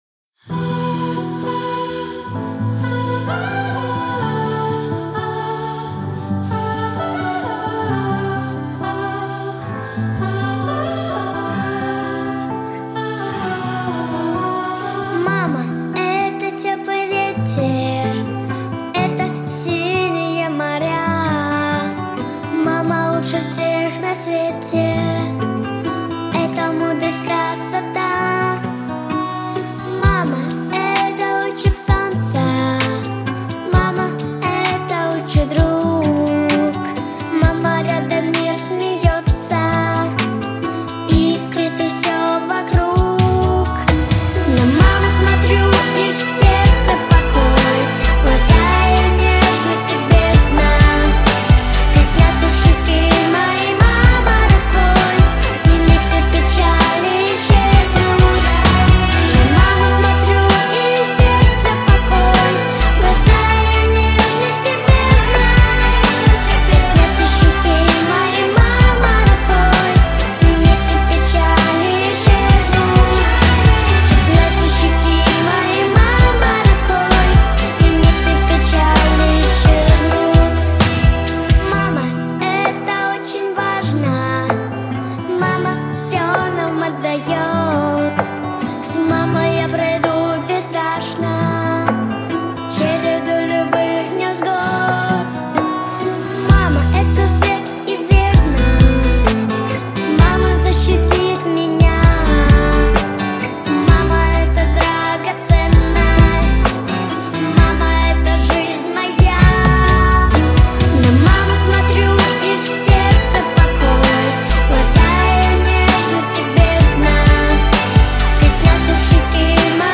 🎶 Детские песни / Песни про маму